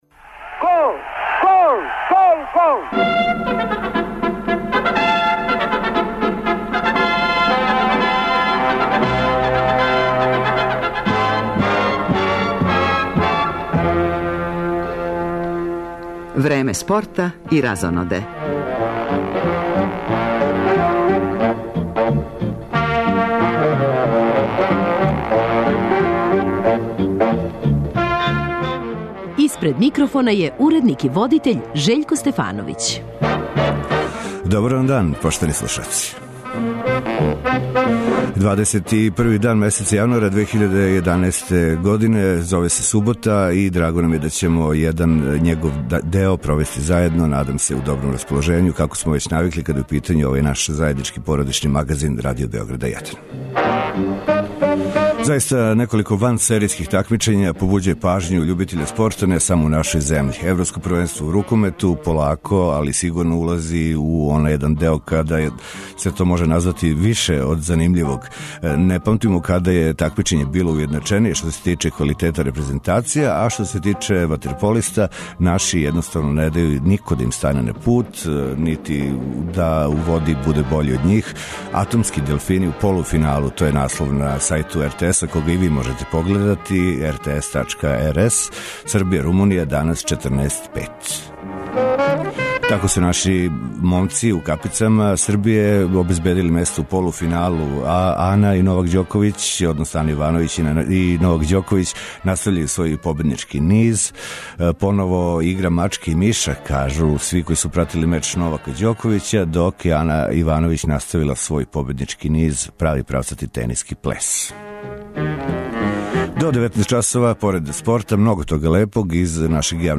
Неколико великих међународних такмичења је у току, па су оправдано на листи приоритета и овог издања породичног магазина Радио Београда 1. Србија је домаћин Европског шампионата у рукомету, чини се да ривали никада нису били уједначенији, па улазак у завршну фазу буди огомно интересовање љубитеља овог спорта.